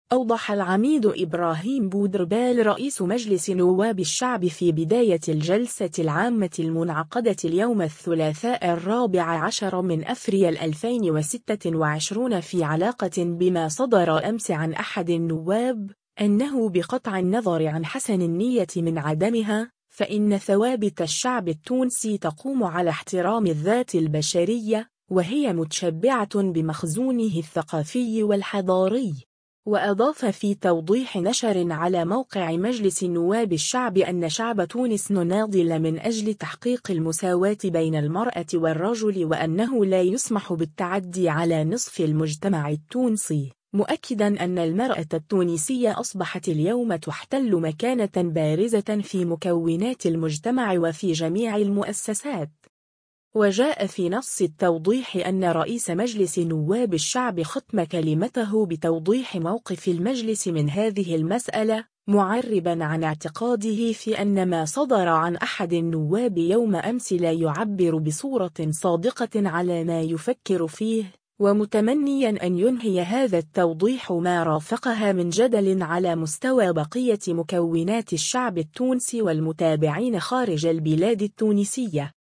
أوضح العميد إبراهيم بودربالة رئيس مجلس نواب الشعب في بداية الجلسة العامة المنعقدة اليوم الثلاثاء 14 أفريل 2026 في علاقة بما صدر أمس عن أحد النواب، “أنّه بقطع النّظر عن حسن النيّة من عدمها، فإن ثوابت الشعب التونسي تقوم على احترام الذات البشرية، وهي متشبّعة بمخزونه الثّقافي والحضاري.